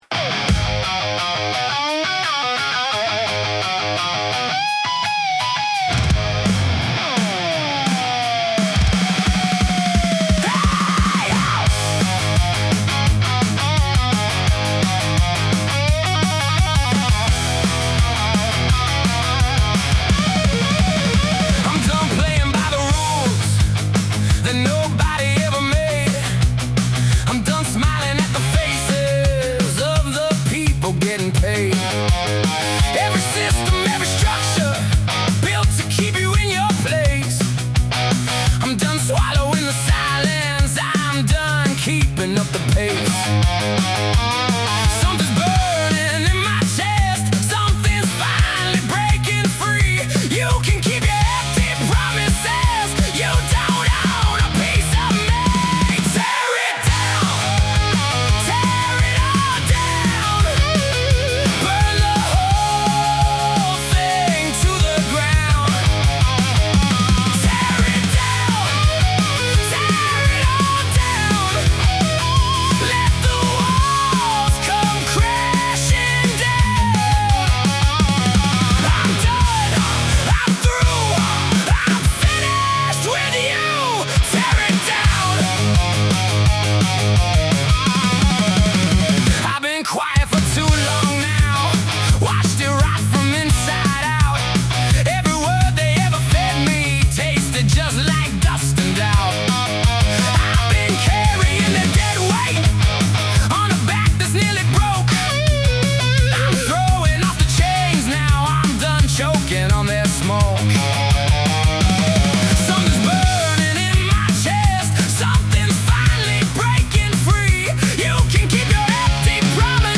Made with Suno
hard rock, garage punk, punk rock